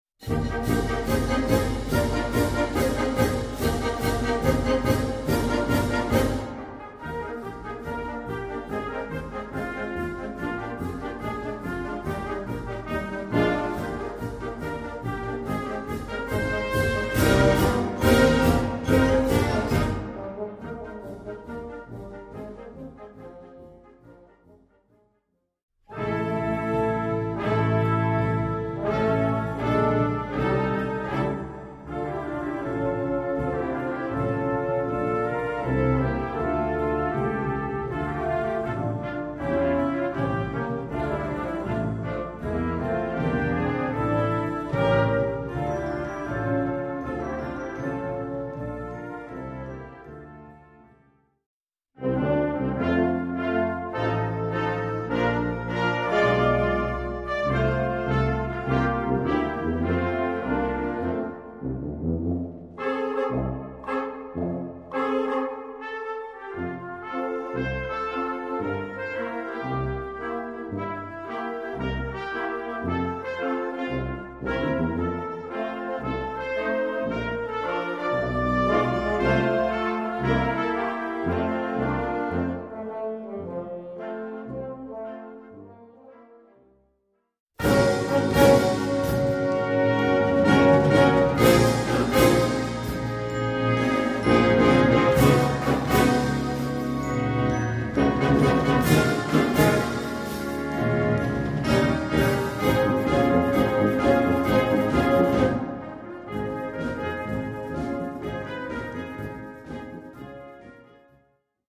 Gattung: für Jugendblasorchester
Besetzung: Blasorchester
im italienischen Stil geschrieben
arabisch angehaucht
im Disco-Stil